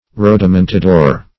Rodomontador \Rod`o*mon*ta"dor\, n. A rodomontadist.